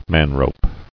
[man·rope]